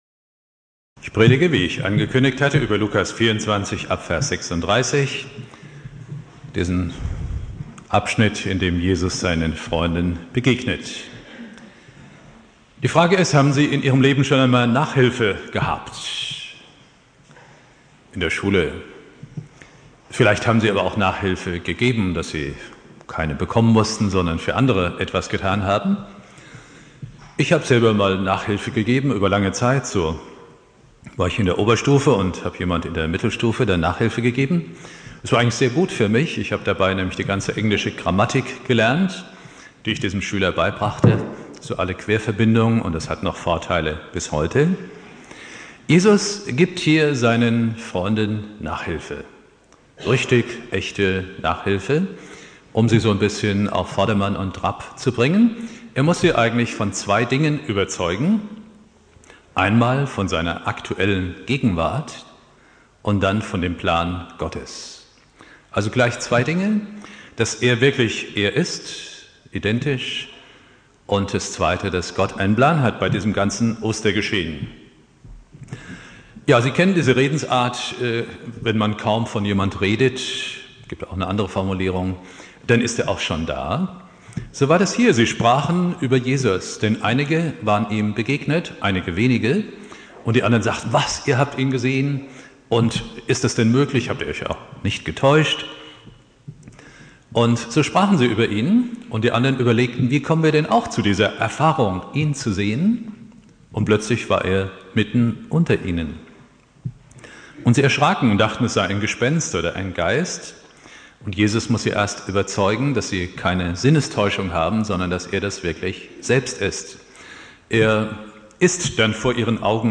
Predigt
Ostermontag Prediger